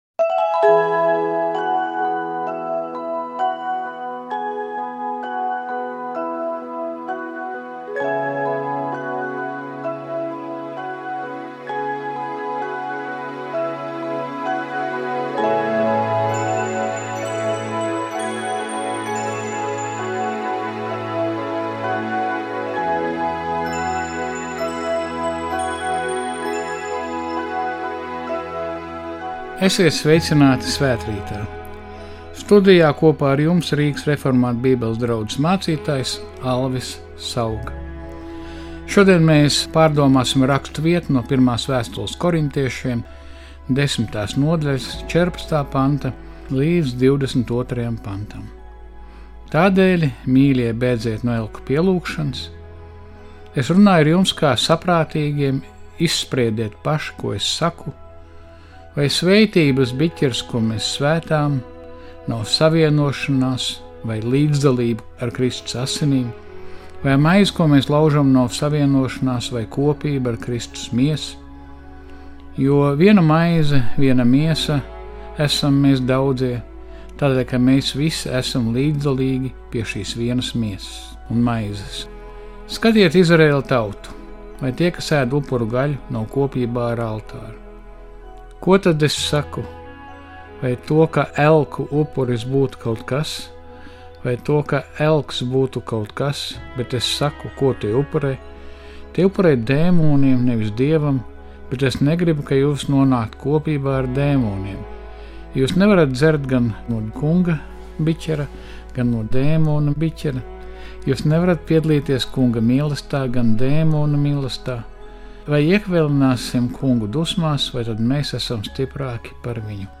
Svtrts Author: Latvijas Radio 1 Language: lv Genres: Religion , Religion & Spirituality Contact email: Get it Feed URL: Get it iTunes ID: Get it Get all podcast data Listen Now... Svētrīts. Studijā mācītājs